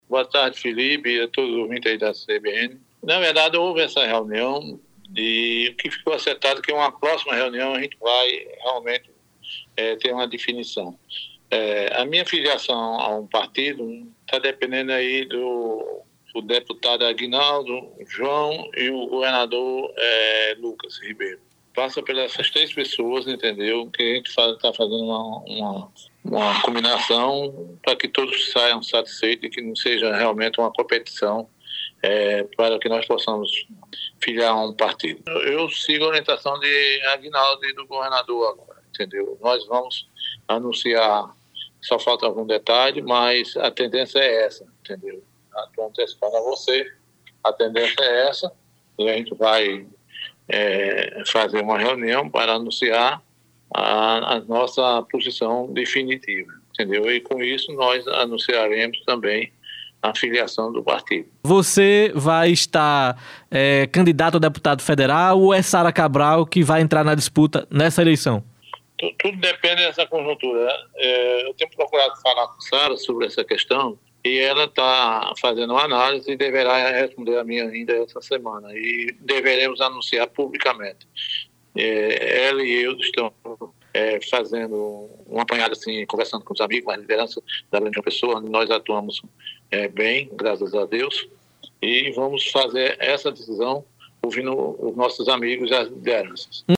O ex-deputado federal Domiciano Cabral disse à rádio CBN, nesta segunda-feira (02), que aguarda o aval do deputado federal Aguinaldo Ribeiro (PP) para decidir sobre seu futuro partidário.